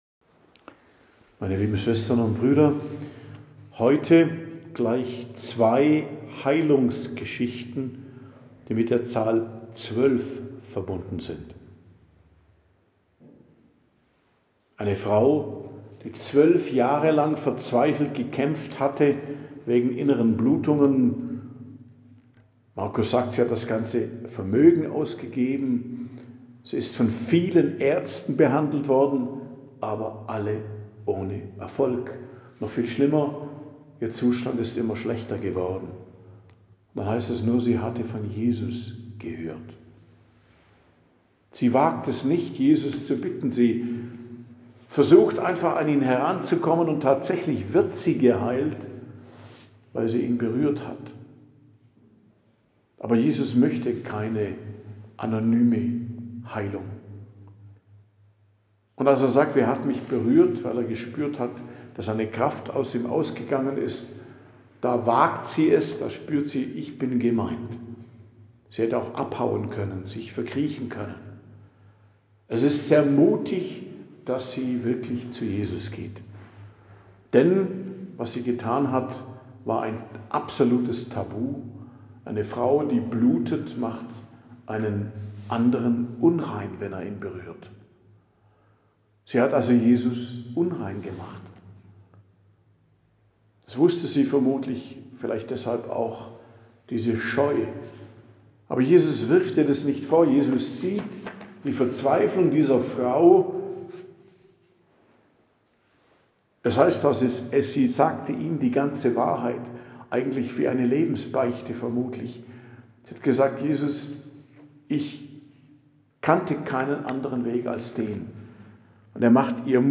Predigt am Dienstag der 4. Woche i.J., 3.02.2026